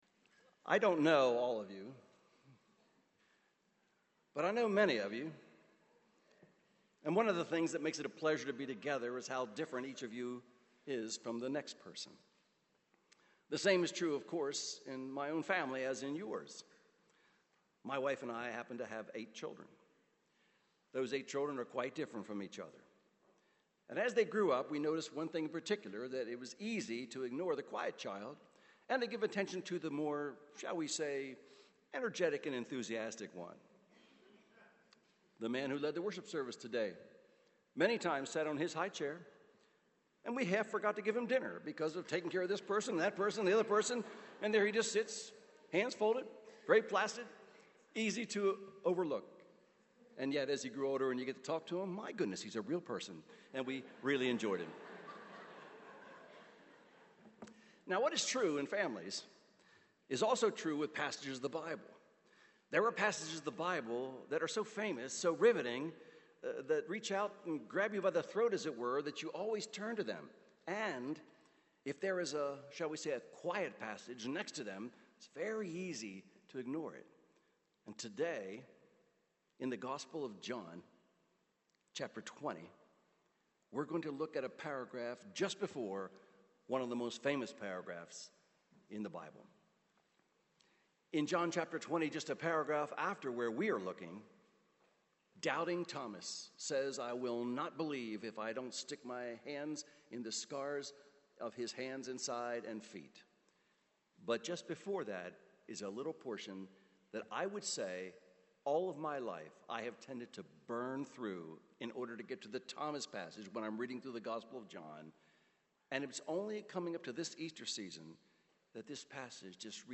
Sermons on John 20:19-23 — Audio Sermons — Brick Lane Community Church